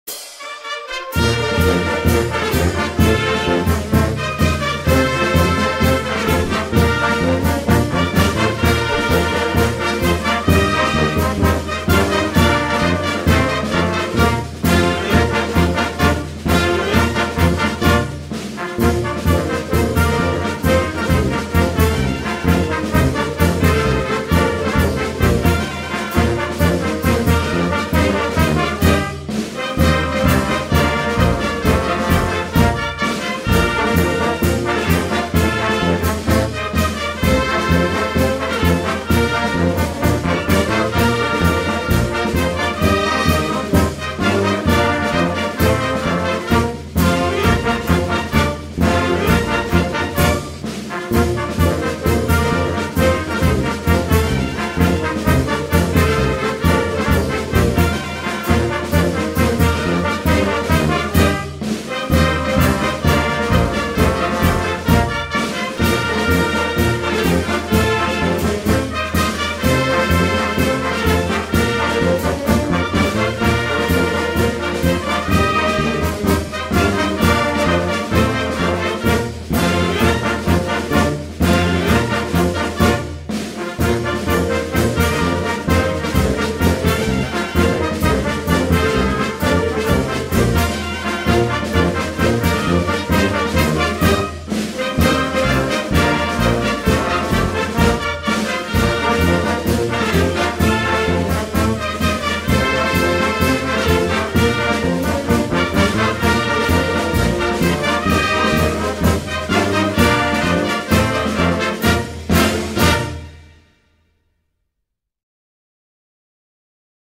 Els nens i nenes de l’escola ens vam aprendre la seva cançó, tot adaptant la lletra de la tonada popular valenciana “La Manta al Coll“… que us deixem aquí per si voleu recordar-la i cantar-la!